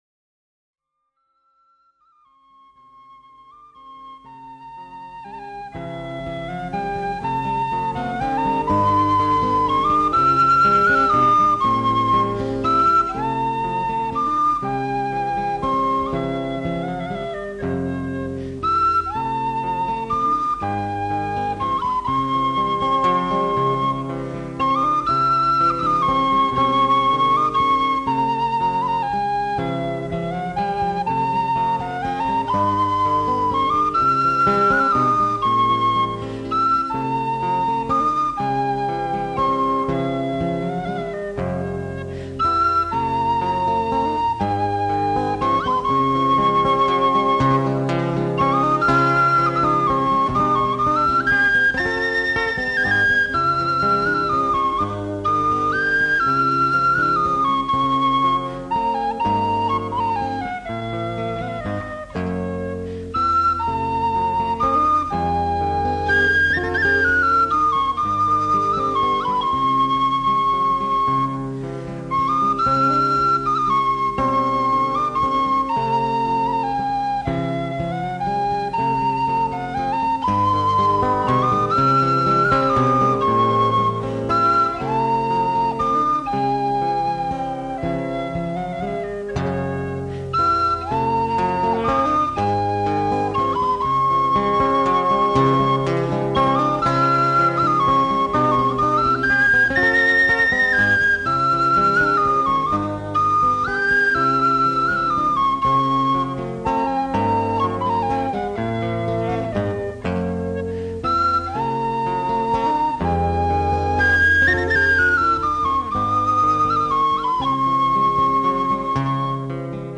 fiddle
whistle
guitar